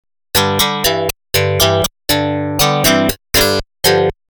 オーディオ・デモ（自作です）
Clean
価格からは信じられないくらい、リアルな音色です。
clean.mp3